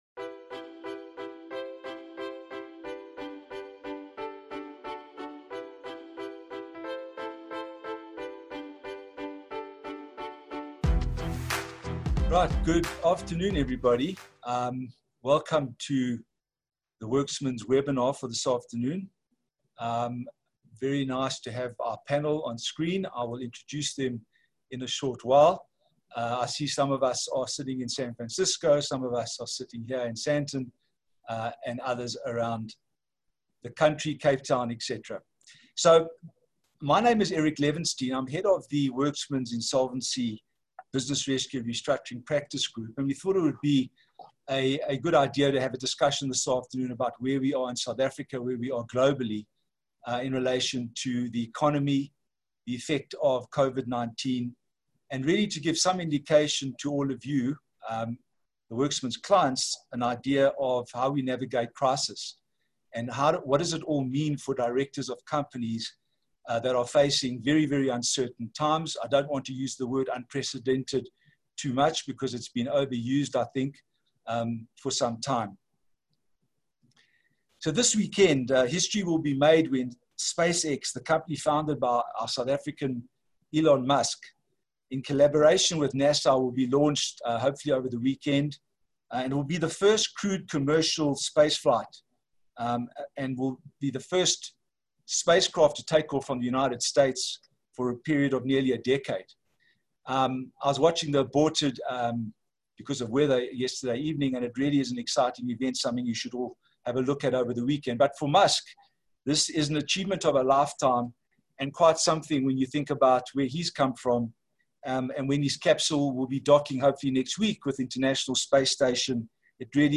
Our panel of experts unpack the impact that the new economic dispensation will have on South African directors of companies and how they are expected to navigate their way around ongoing obstacles and challenges.